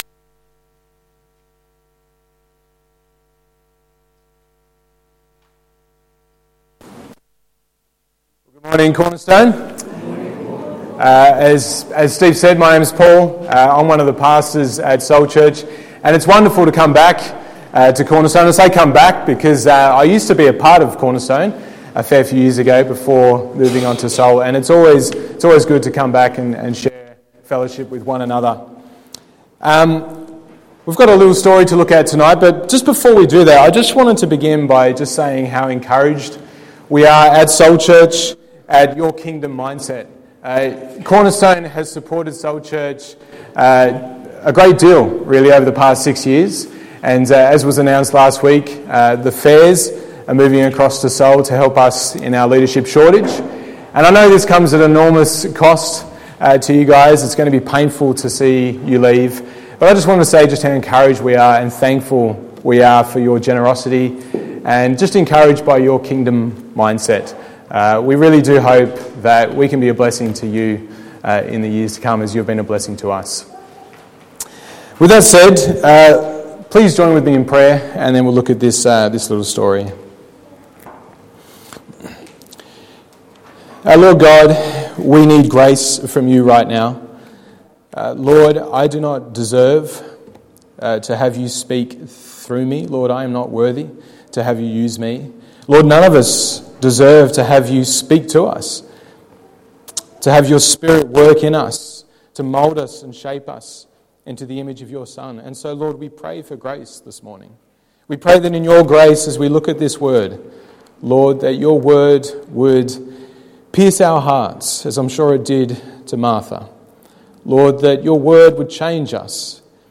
Luke 10:38-41 Sermon